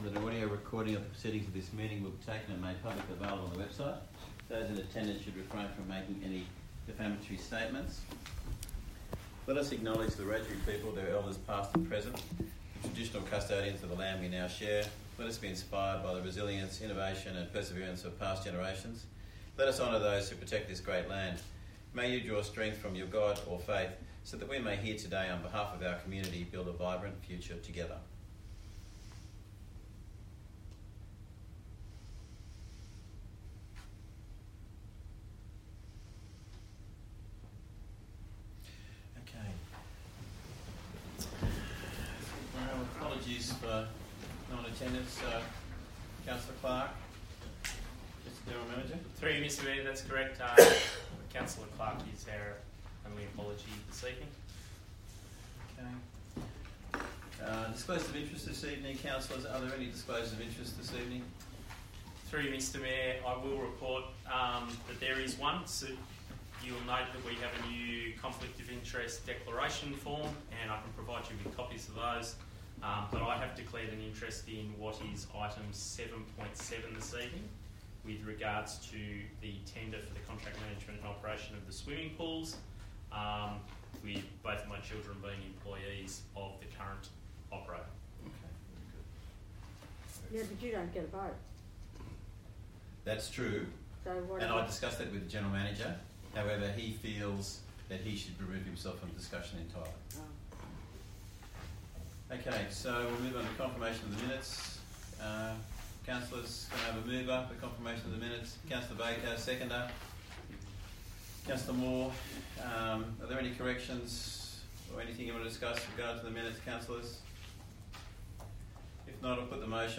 16 May 2023 Ordinary Meeting